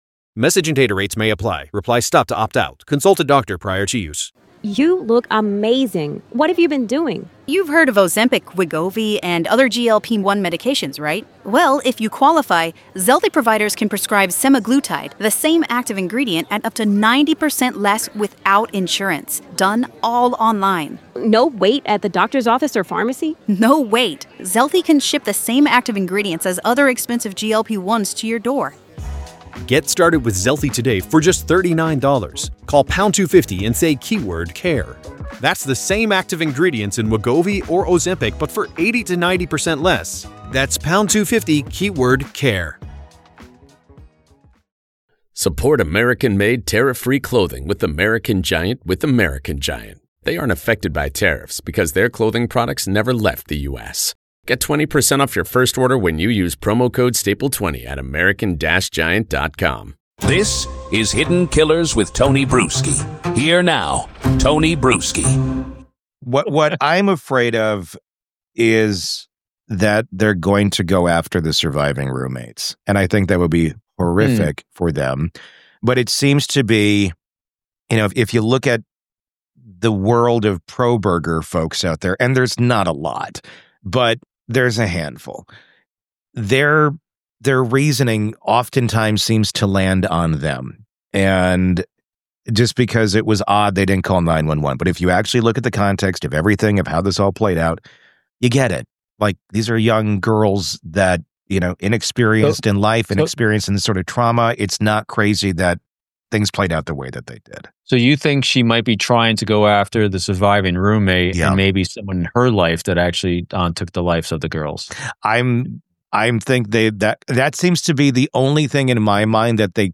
If you think you’ve seen defense tactics go low, this conversation asks the question: how far is too far in the pursuit of reasonable doubt?